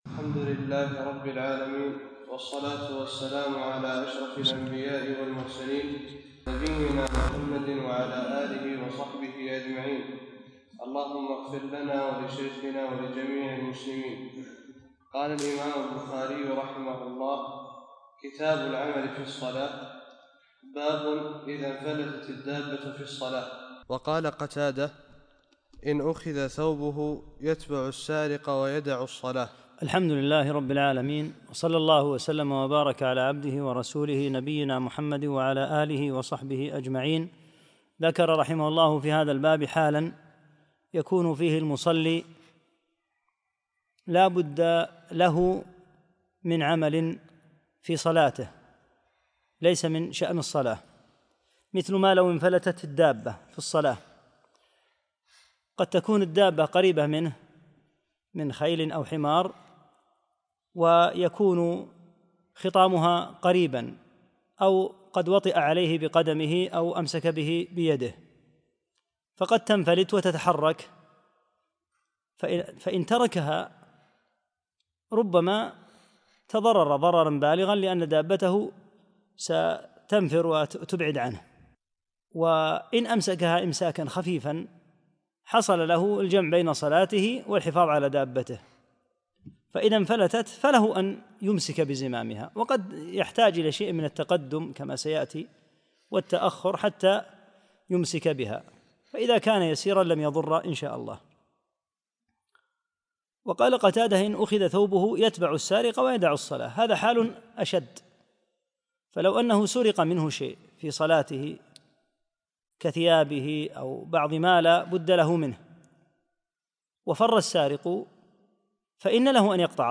2- الدرس الثاني